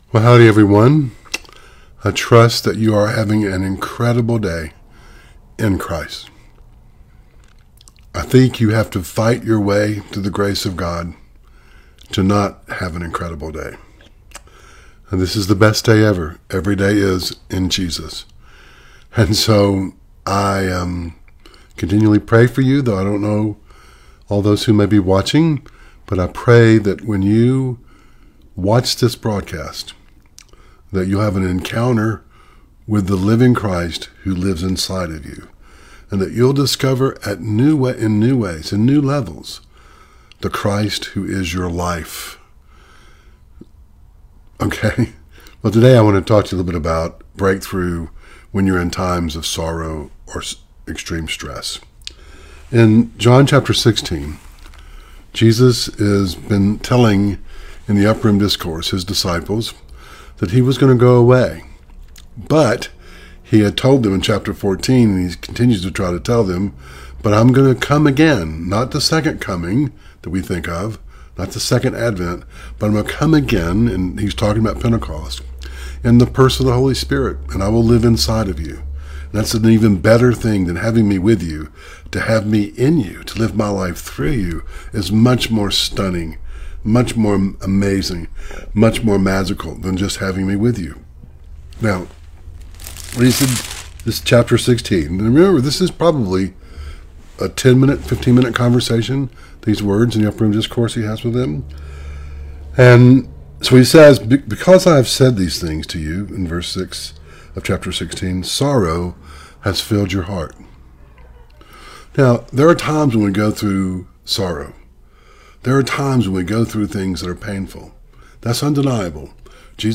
Series: Audio Devotionals Service Type: Facebook Live Share this